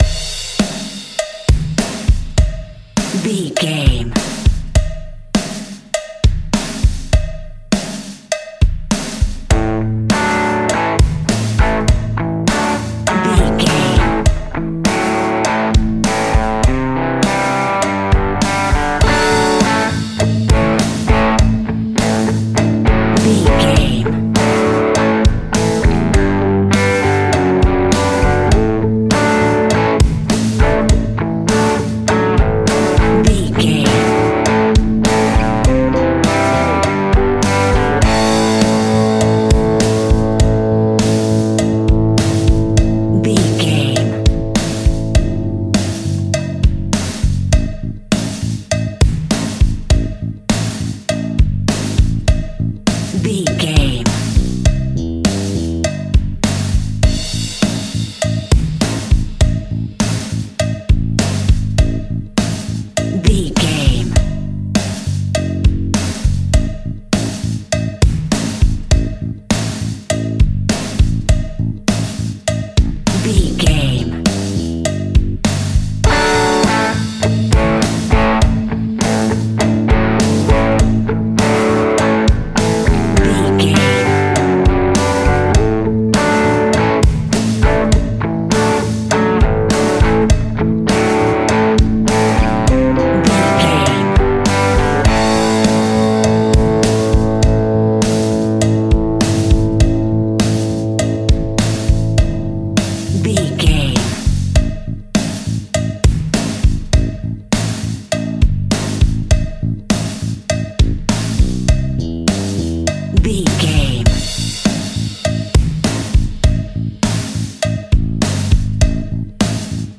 Epic / Action
Fast paced
Mixolydian
hard rock
blues rock
distortion
instrumentals
Rock Bass
Rock Drums
heavy drums
distorted guitars
hammond organ